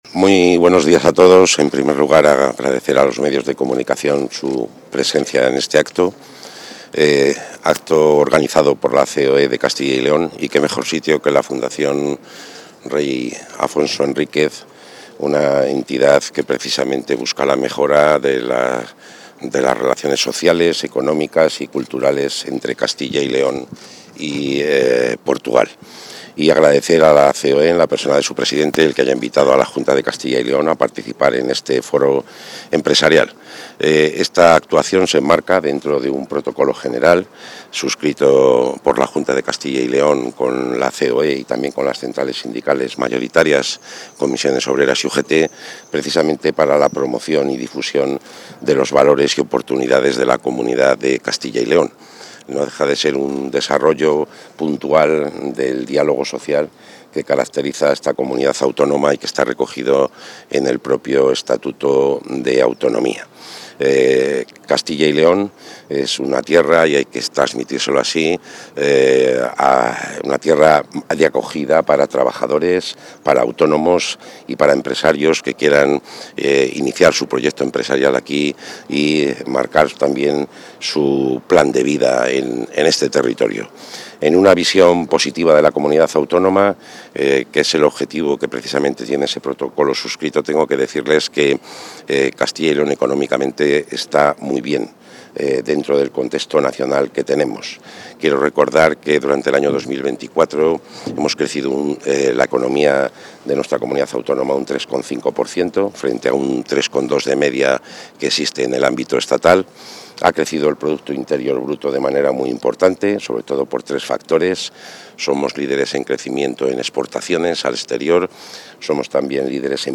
González Gago ha participado en la inauguración del Foro Empresarial Castilla y León – Región Norte de Portugal,...
Intervención del consejero.